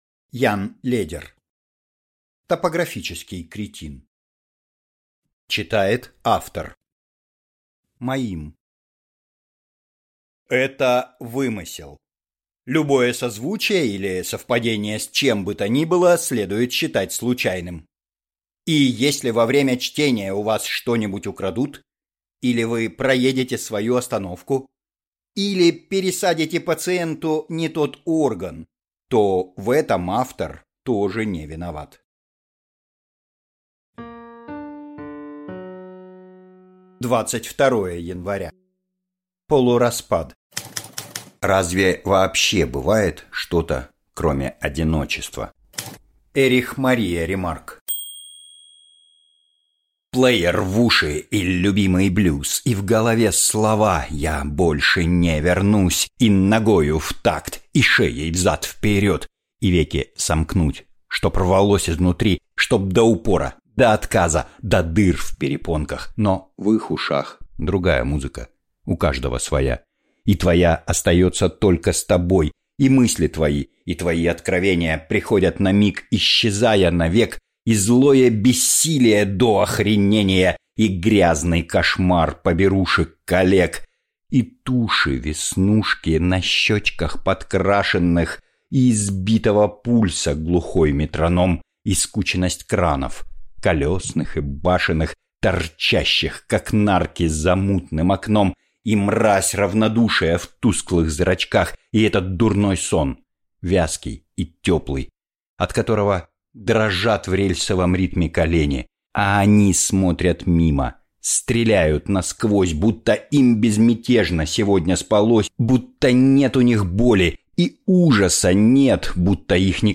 Аудиокнига Топографический кретин | Библиотека аудиокниг